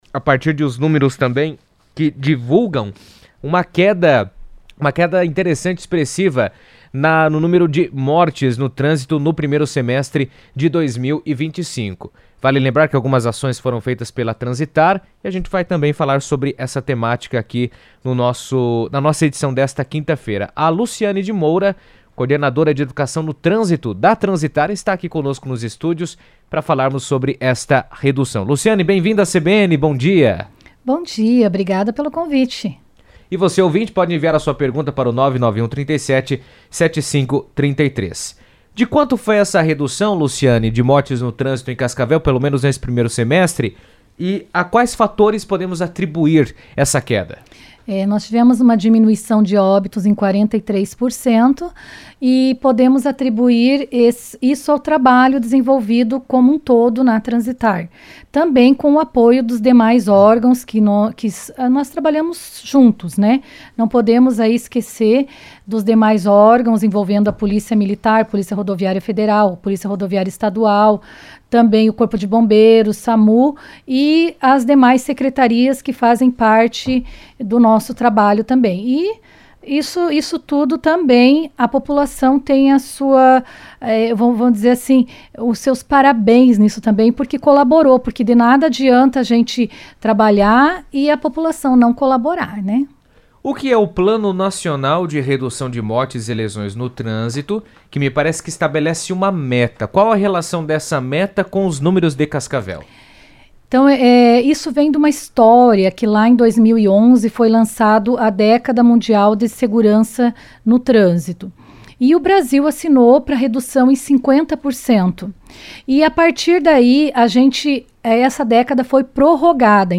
esteve na CBN destacando a importância das iniciativas de conscientização para alcançar esse avanço.